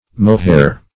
Mohair \Mo"hair`\, n. [F. moire, perh. from Ar. mukhayyar a kind